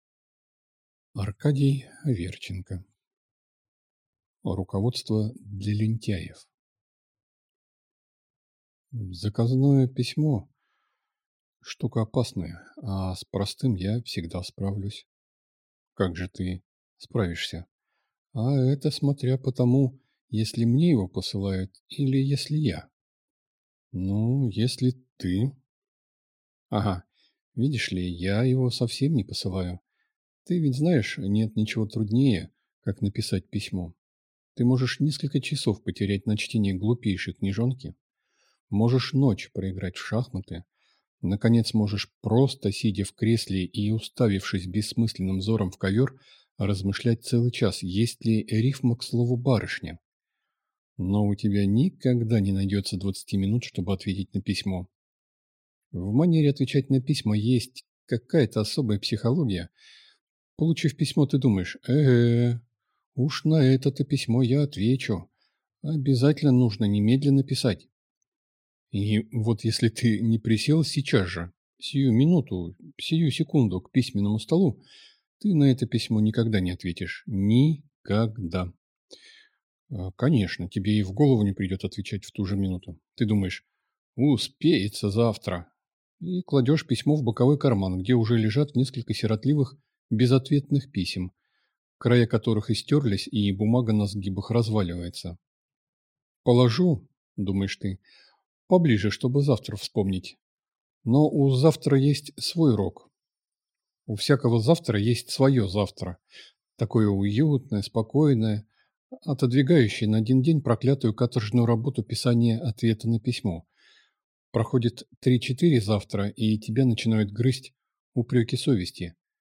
Аудиокнига Руководство для лентяев | Библиотека аудиокниг